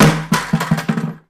Звуки падения, грохота
Пластиковое ведро упало